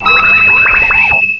cry_not_beheeyem.aif